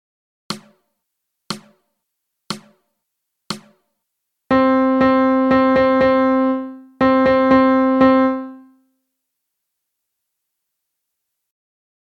Then with “Read Rhythms” you get plenty of practice with increasingly complex rhythm patterns. You’ll learn to match up the score representation with the sound you hear and vice-versa.